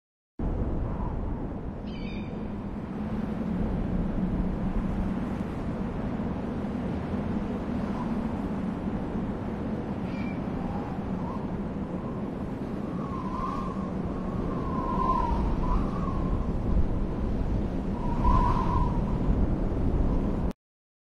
SONIDO AMBIENTE DESIERTO
Un ambiente relajado del desierto
Aquí encontrarás un efecto sonoro realista que captura el susurro del viento sobre la arena y la sensación de un paisaje sin fin.
Es un susurro constante del viento moviendo la arena, es la sensación de vacío, de inmensidad y de tiempo detenido.
Sonido-desierto.wav